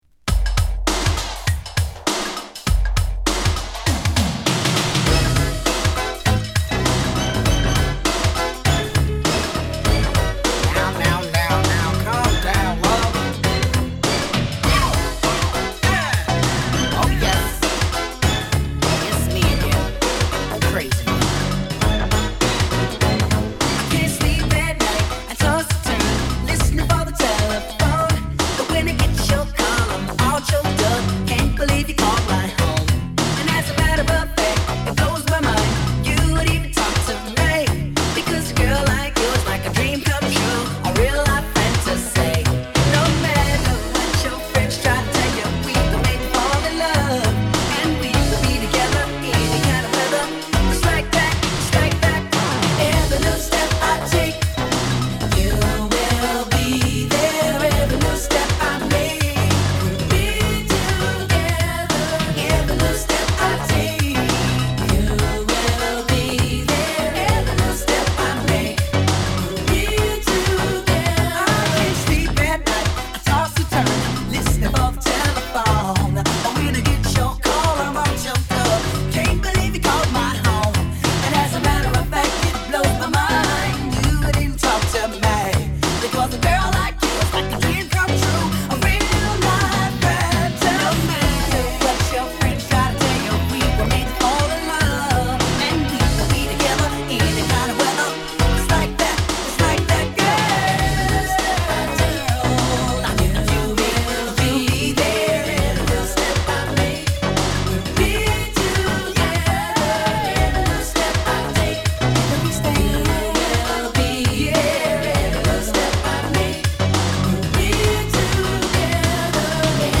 弾んだNJSなビートに甘酸っぱいヴォーカルが乗る名曲！